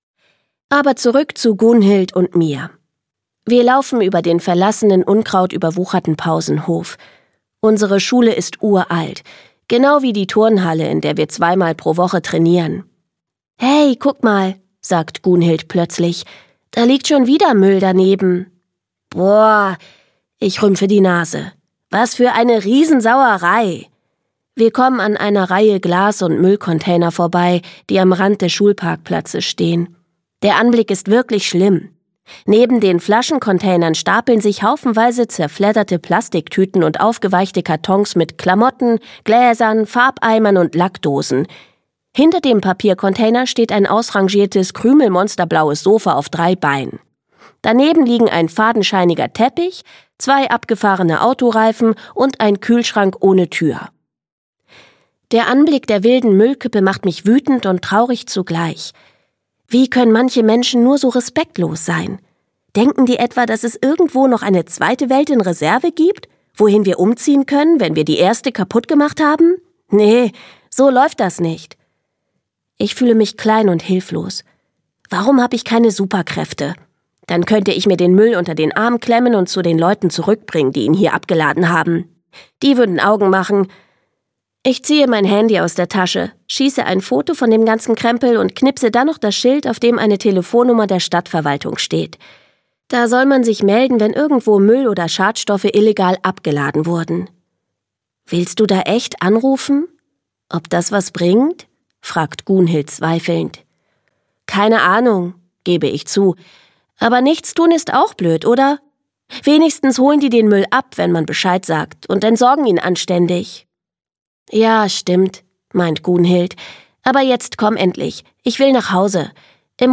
Jella hat genug! - Dagmar Hoßfeld - Hörbuch